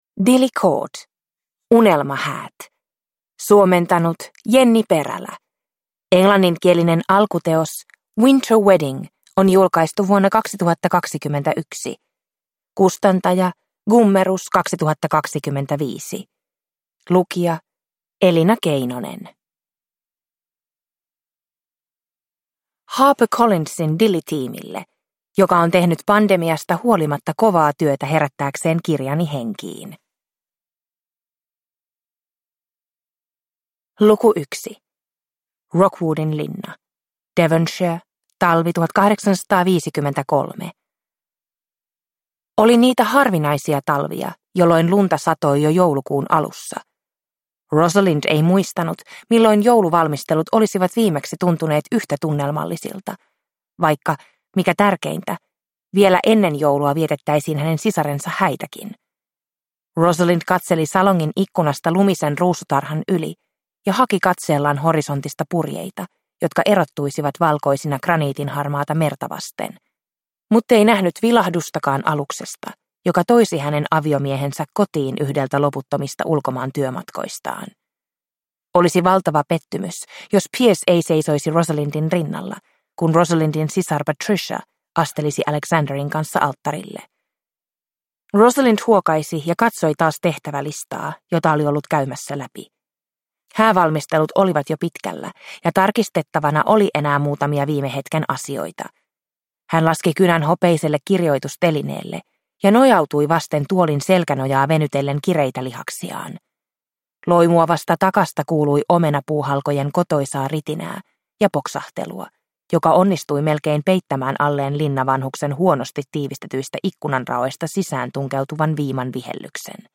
Unelmahäät (ljudbok) av Dilly Court